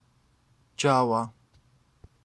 Java or Dzau (Georgian: ჯავა [dʒava]